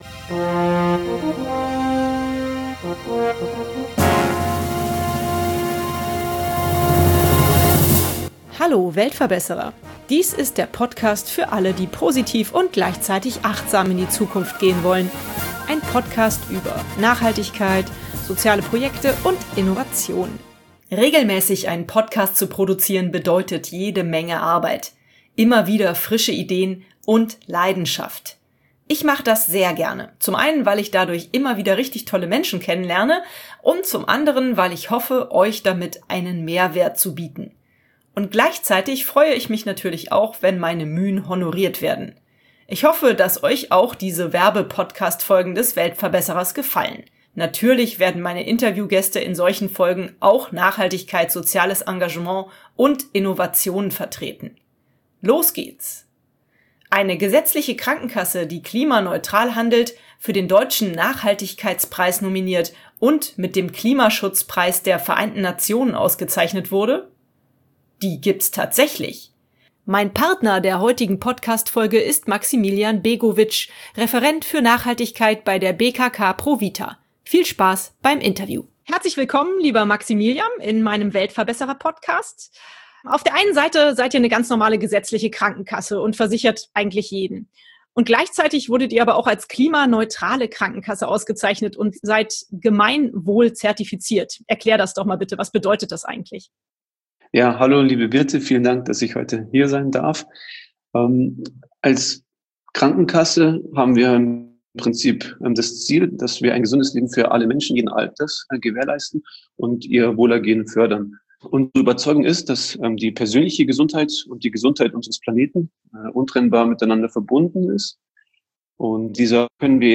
Viel Spaß beim Interview! -DAUERWERBESENDUNG - Mehr